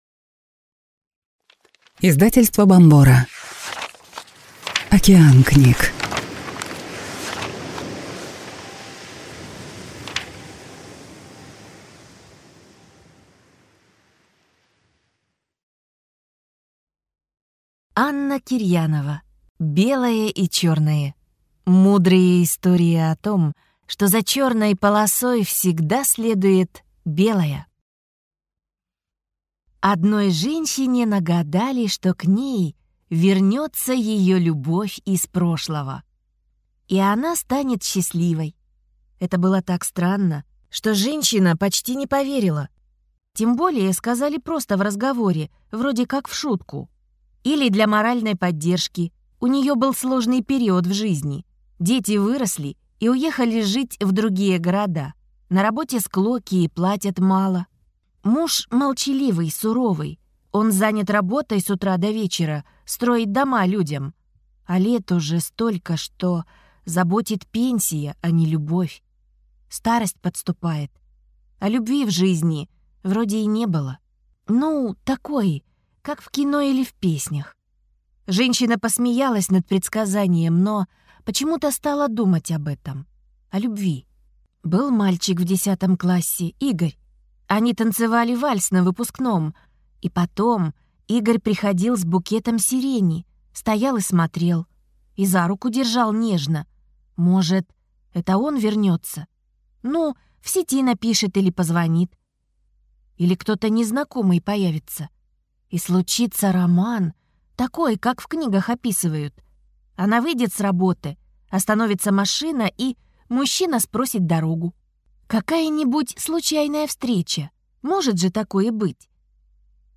Аудиокнига Белое и черное. Мудрые истории о том, что за черной полосой всегда следует белая | Библиотека аудиокниг